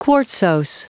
Prononciation du mot quartzose en anglais (fichier audio)
Prononciation du mot : quartzose